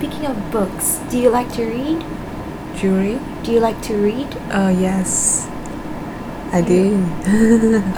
S1 = Brunei female S2 = Chinese female
It seems that S2 misheard the aspiration on the [t] at the start of to read as the affricate [dʒ] .
When S1 repeats the question with the final [d] said even more clearly, then S2 understands it and responds appropriately.